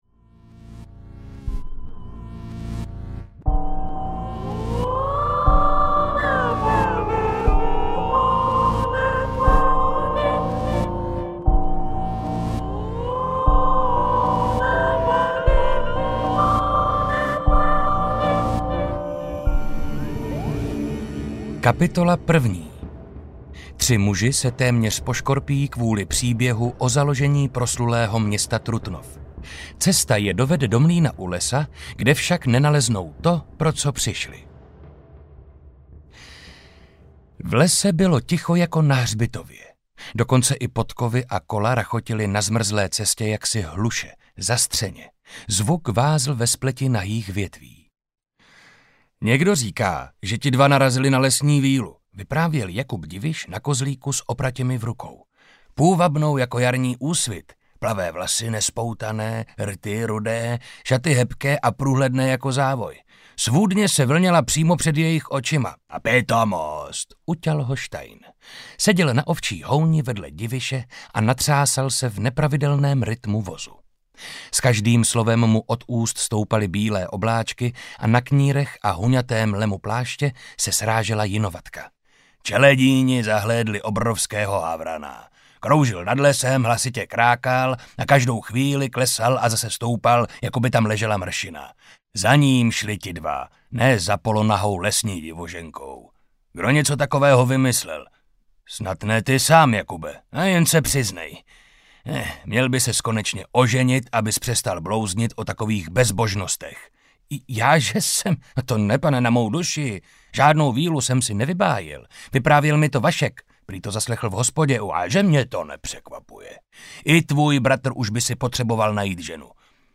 Les přízraků audiokniha
Ukázka z knihy
• InterpretMarek Holý